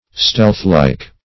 Stealthlike \Stealth"like`\ (-l[imac]k`)
stealthlike.mp3